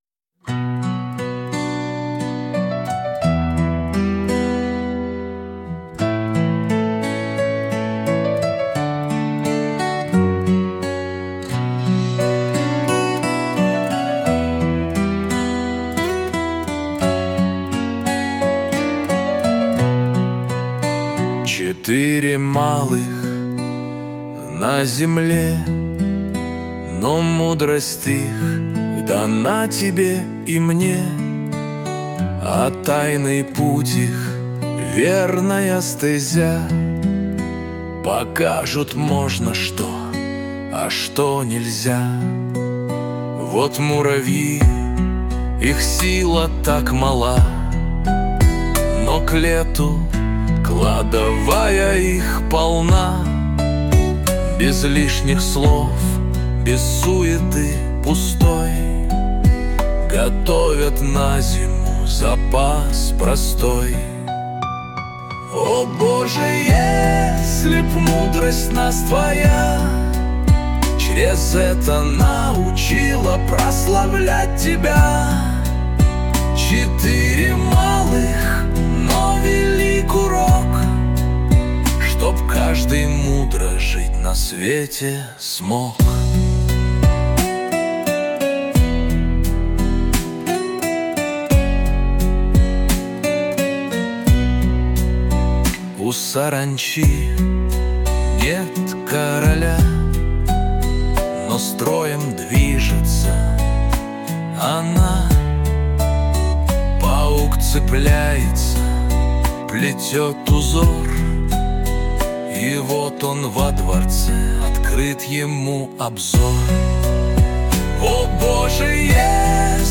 песня ai
162 просмотра 896 прослушиваний 38 скачиваний BPM: 87